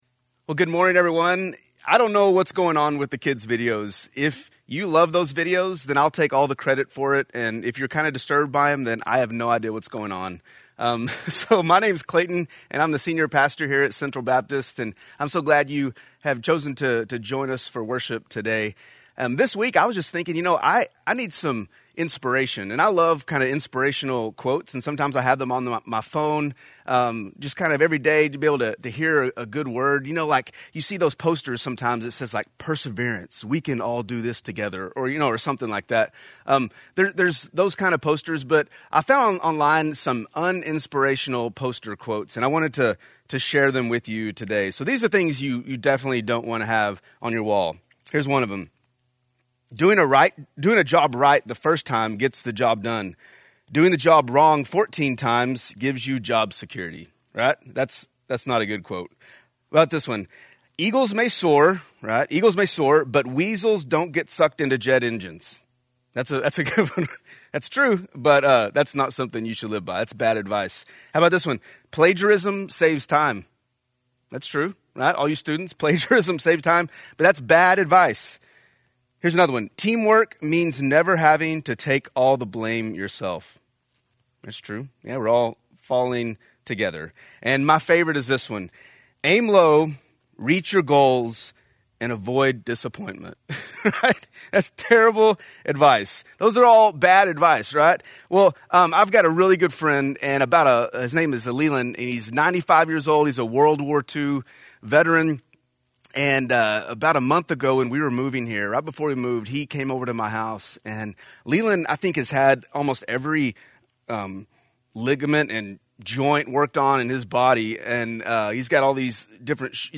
Message
A message from the series "Asking for a Friend."